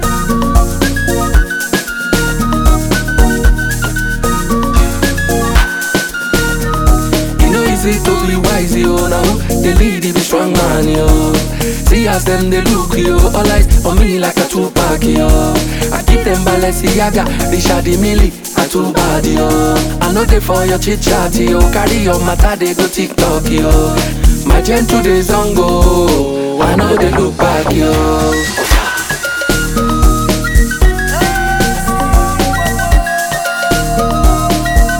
Жанр: Танцевальные / Русские